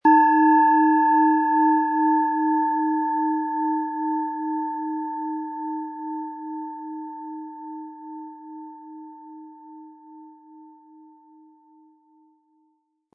Tibetische Bauch-Kopf- und Herz-Klangschale, Ø 11,7 cm, 180-260 Gramm, mit Klöppel
Beim Aufnehmen für den Shop spielen wir die Klangschale an und versuchen zu ermitteln, welche Bereiche des Körpers angesprochen werden.
Im Sound-Player - Jetzt reinhören können Sie den Original-Ton genau dieser Schale anhören.
SchalenformBihar
MaterialBronze